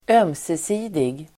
Ladda ner uttalet
ömse|sidig adjektiv, mutual Uttal: [²'öm:sesi:dig] Böjningar: ömsesidigt, ömsesidiga Definition: som gäller två parter; inbördes, gemensam Exempel: visa ömsesidig förståelse (show mutual understanding)